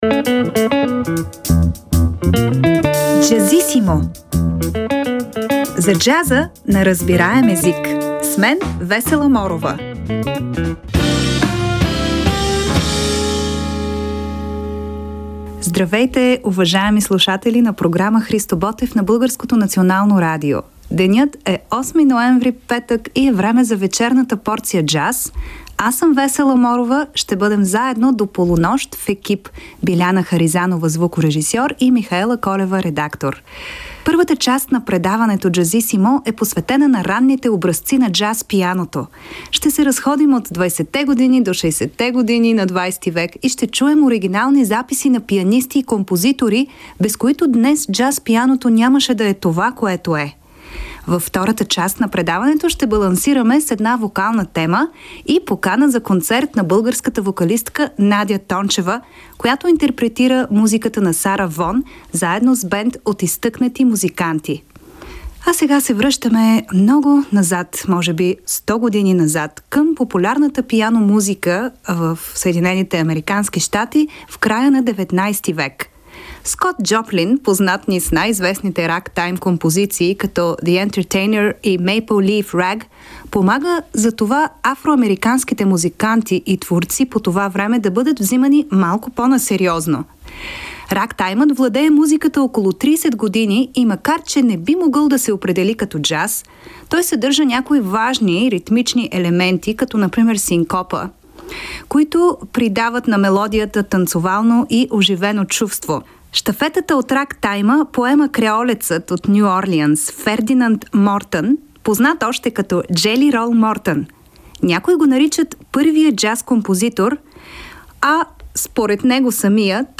в интервю